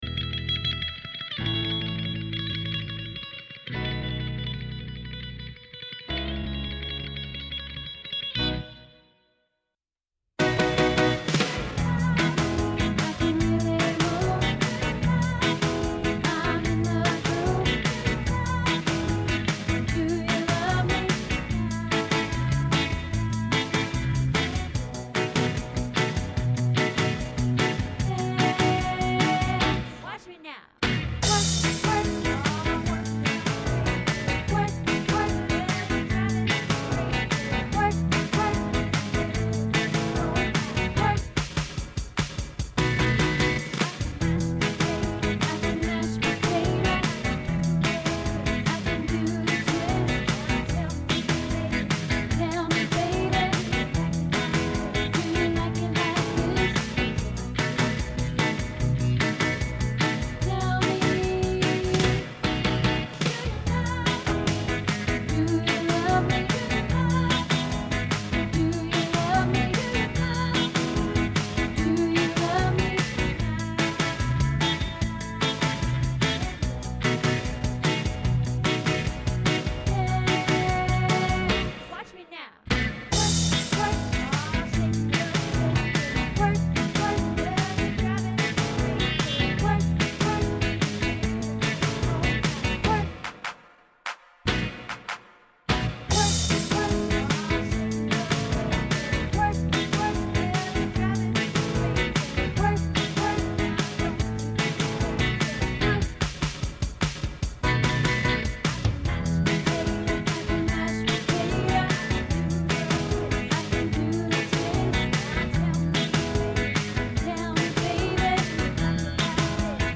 Do You Love Me Tenor